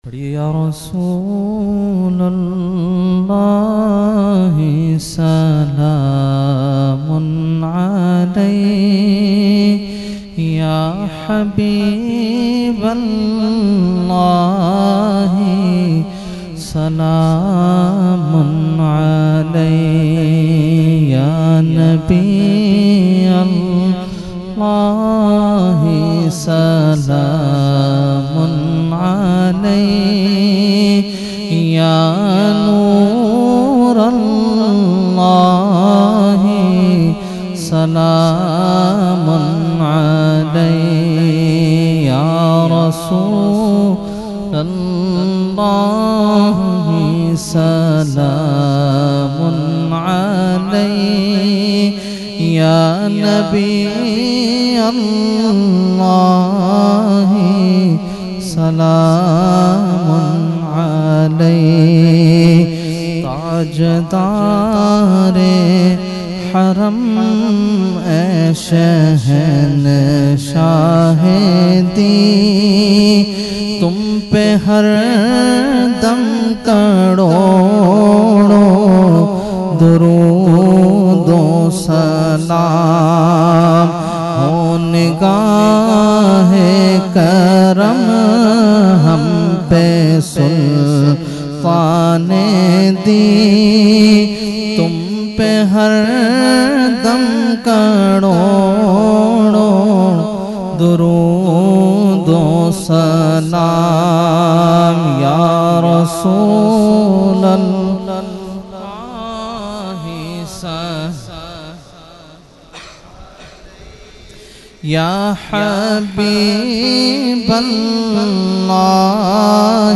Mehfil e Jashne Subhe Baharan held on 16 September 2024 at Dargah Alia Ashrafia Ashrafabad Firdous Colony Gulbahar Karachi.
Category : Salam | Language : UrduEvent : Jashne Subah Baharan 2024